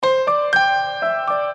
• Качество: 320, Stereo
без слов
пианино
Приятная мелодия под сообщения